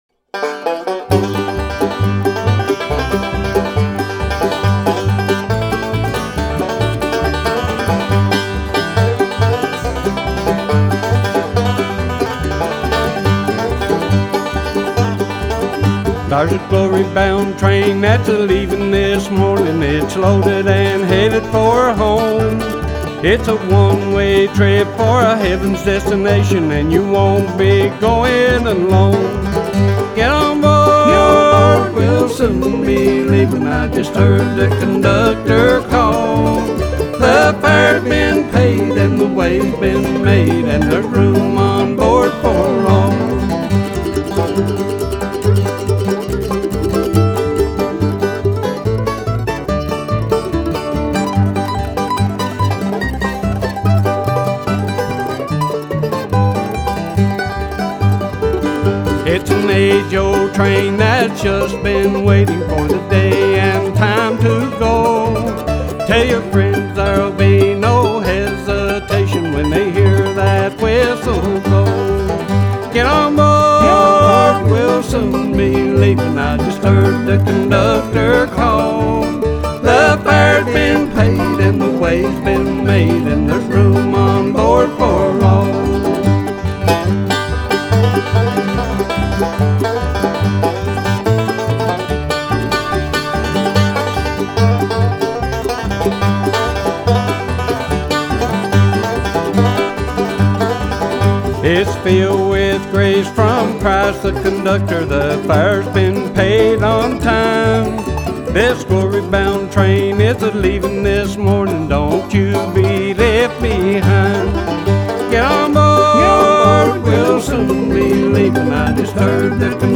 This is American traditional music at its very best.